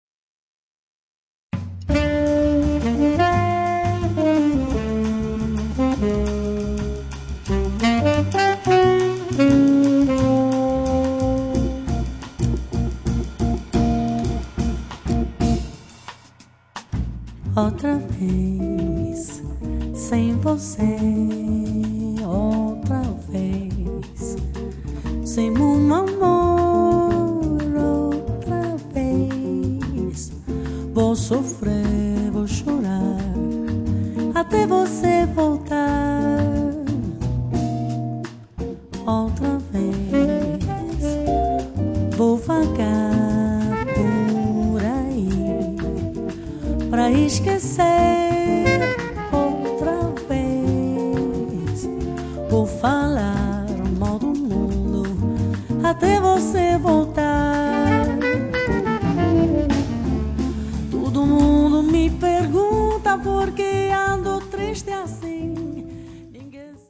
vocal, arrangements
Saxophone
guitar
double bass
drums
Brani raffinati, di corposa eleganza.